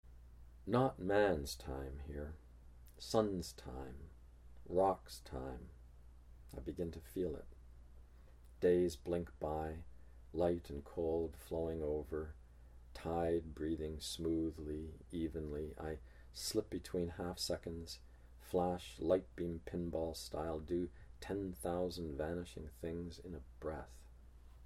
John Steffler reads [not man's time here] from The Grey Islands